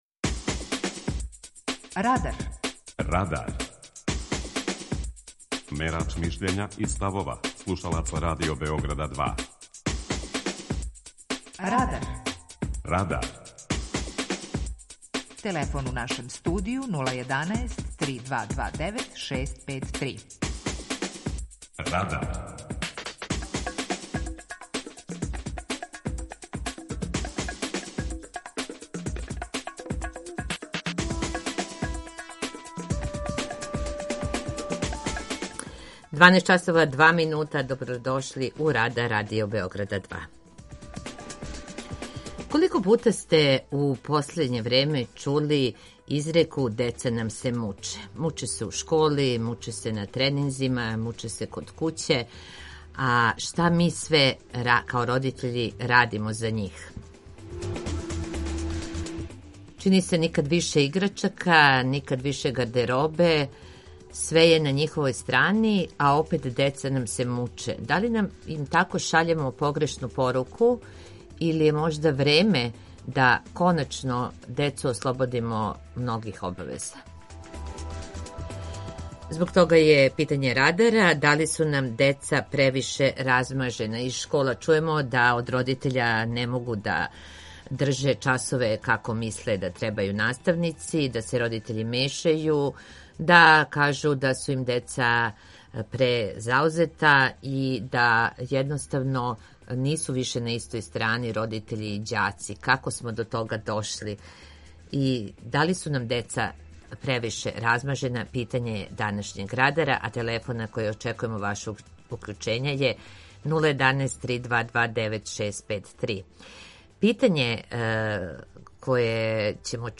Питање Радара је: Да ли су нам деца превише размажена? преузми : 19.27 MB Радар Autor: Група аутора У емисији „Радар", гости и слушаоци разговарају о актуелним темама из друштвеног и културног живота.